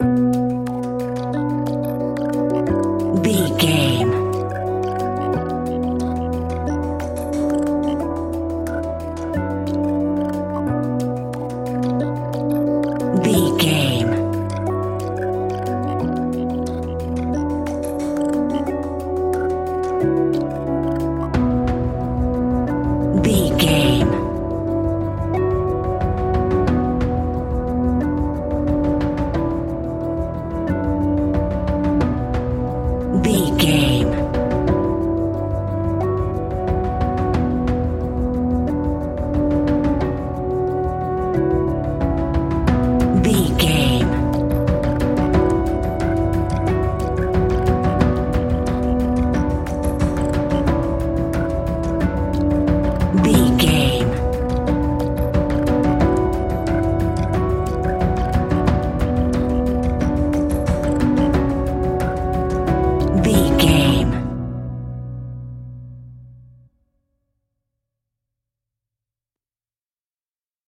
Aeolian/Minor
ominous
dark
eerie
synthesiser
drums
flute
horror music
horror instrumentals